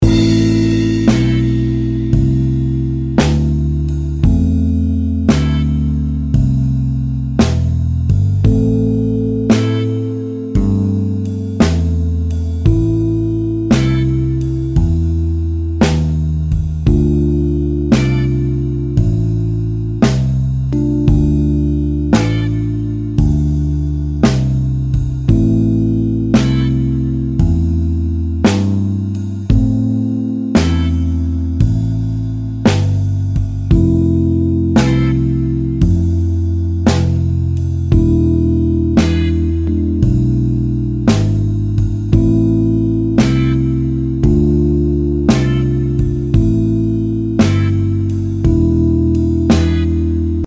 PIu' di 3 settimane per 50 secondi di improvvisazione su un blues minore il LA credo siano necessarie soltanto a chi non è stimolato a partecipare o a chi è totalmente impossibilitato.
Credo sia la sfumatura iniziale che trae in inganno.
slow-blues-jam_loop.mp3